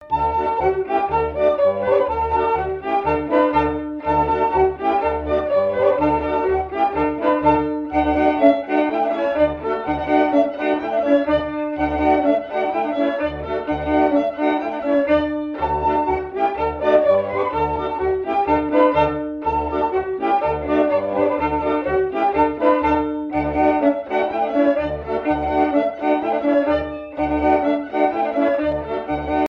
Marais Breton Vendéen
danse : branle : courante, maraîchine
Pièce musicale éditée